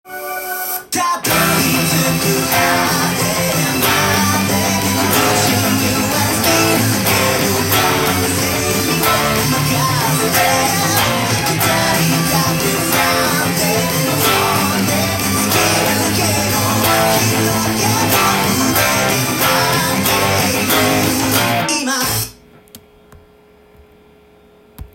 音源にあわせて譜面通りに弾いてみました
オリンピックの応援ソングらしいエモーショナルロック系の曲です！
パワーコードで弾けばカンタンに弾くことが出来ます！